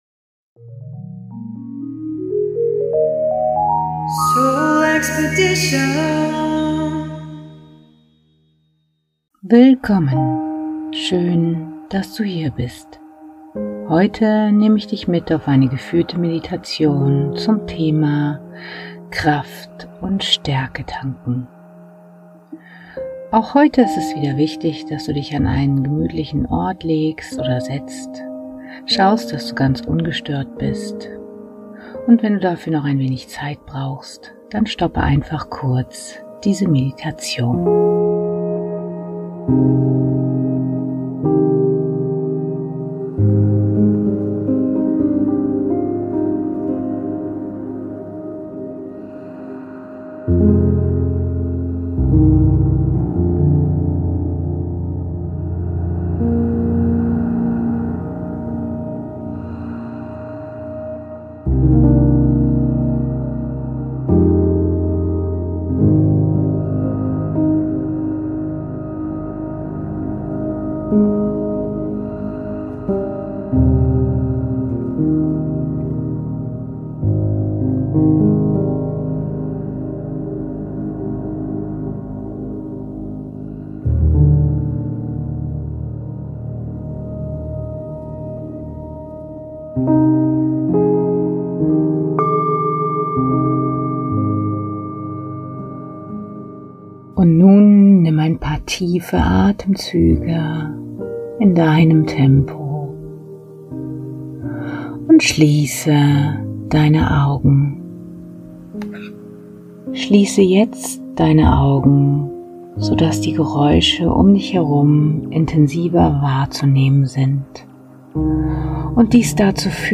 Bitte benutze Kopfhörer für einen optimalen Hörgenuss! Würdest Du gerne wieder in die Stärke und Kraft kommen, die Du schon so lange vermisst?
Ich führe Dich in einen tief entspannten Zustand, wo Du, wenn Du möchtest, auch drüber einschlafen kannst und Dein Unterbewusstsein im Schlaf die Arbeit macht, die nötig ist.